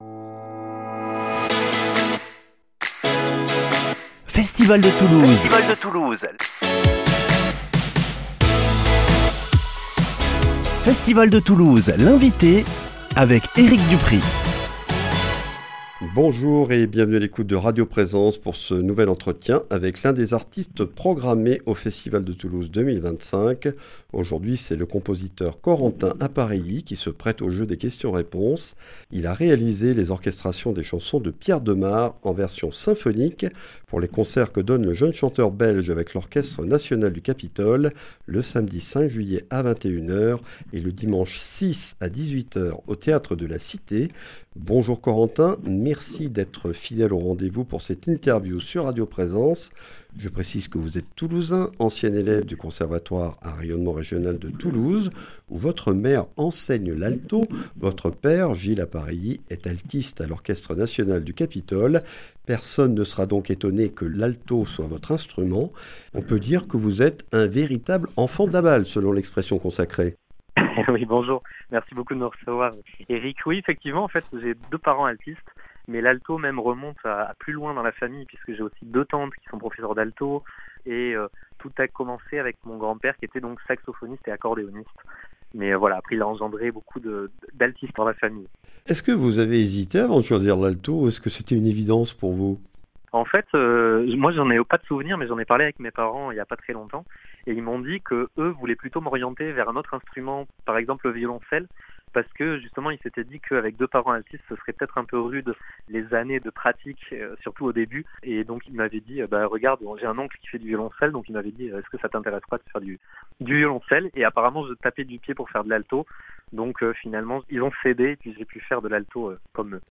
Festival de Toulouse 2025 - Interview